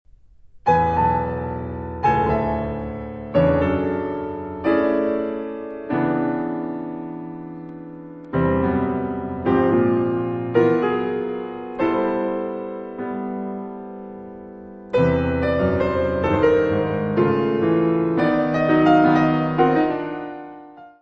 : stereo; 12 cm
Área:  Música Clássica